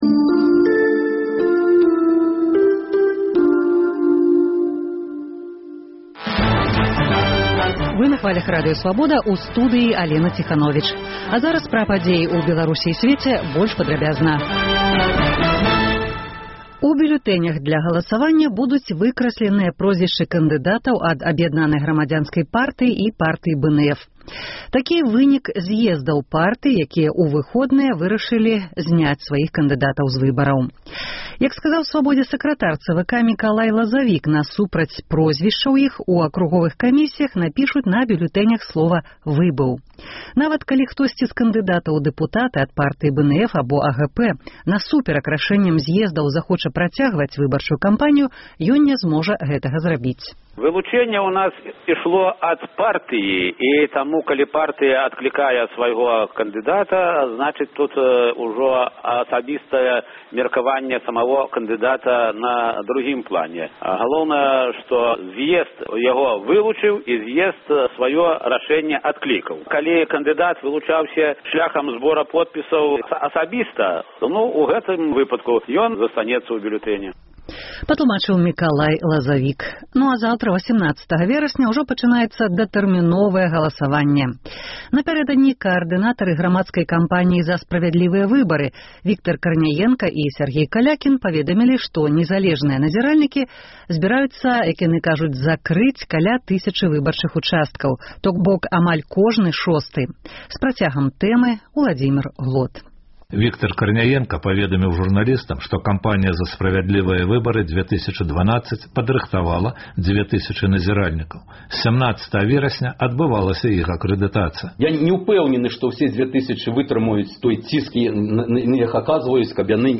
Падсумаваньне дня, бліц-аналіз, галасы людзей.